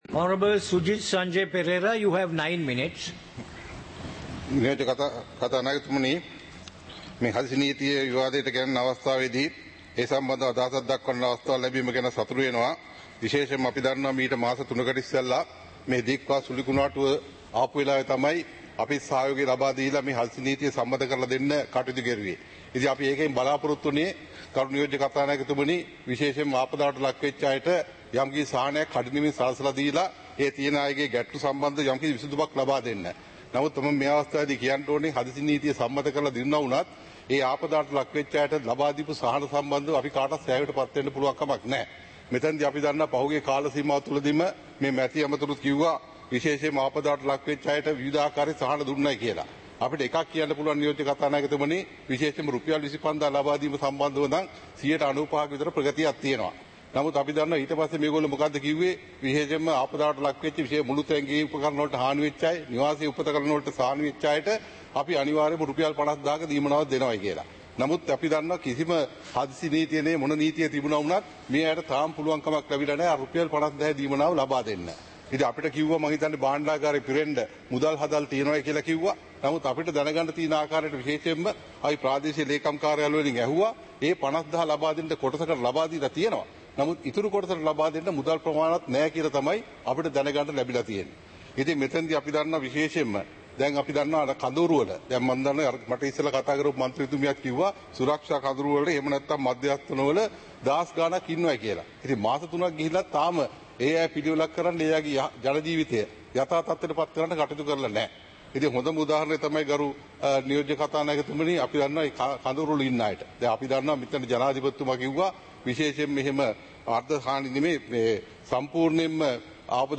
Proceedings of the House (2026-03-06)
Parliament Live - Recorded